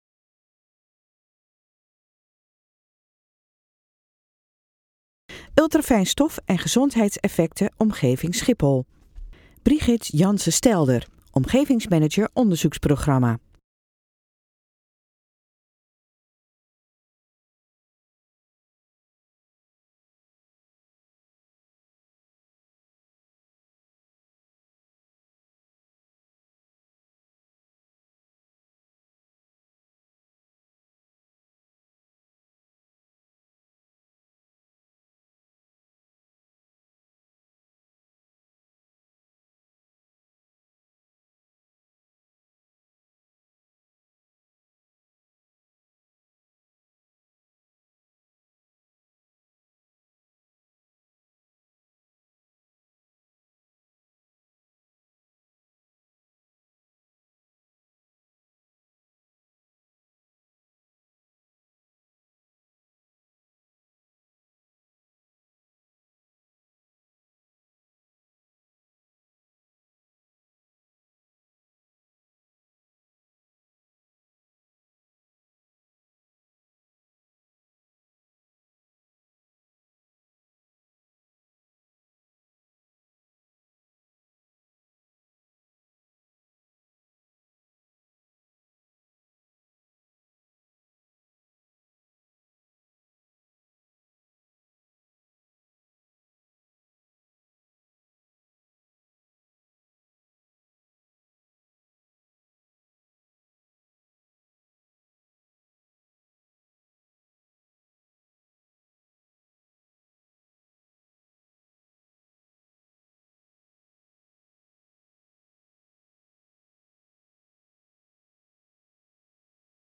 Spreker